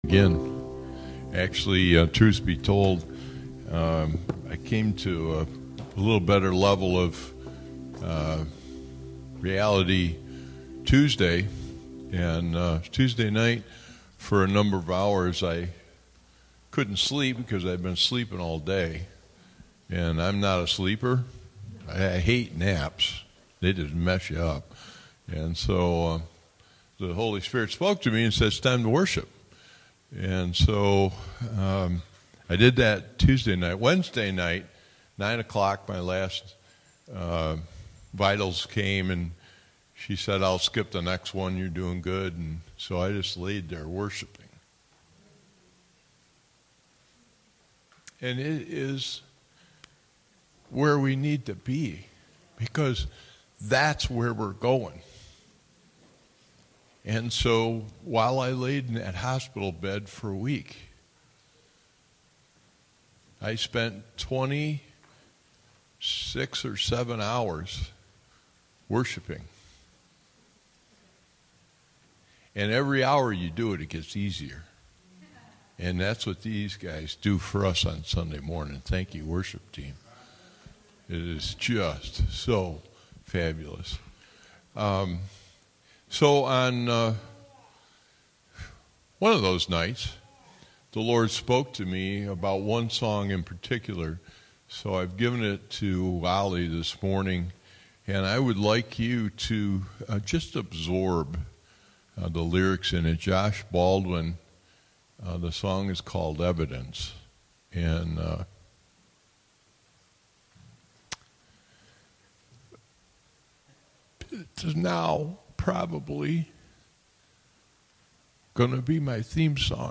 Post category: Messages